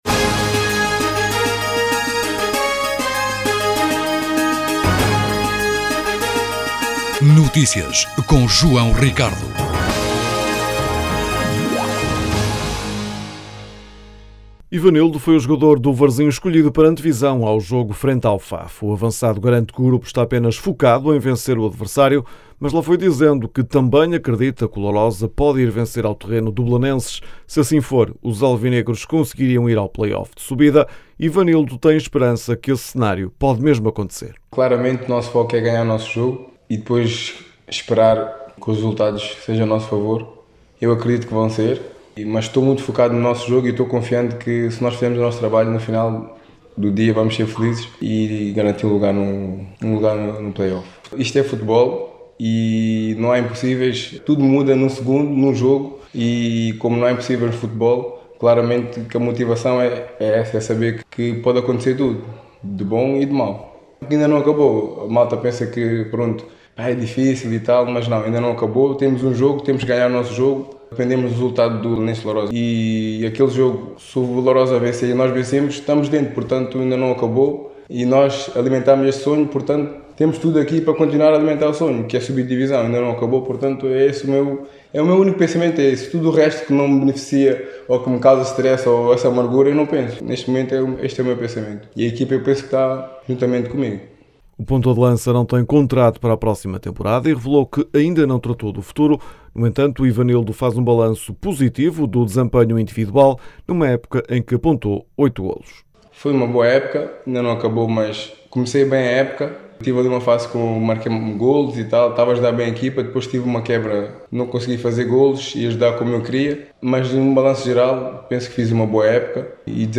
Na abertura do certame, o edil Guilherme Emílio sublinhou a importância desta feira para as entidades envolvidas e para os participantes.